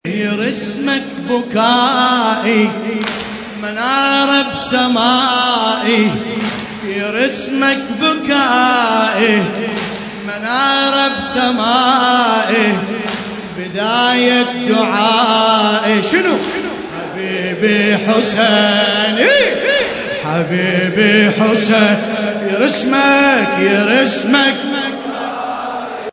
مع لطم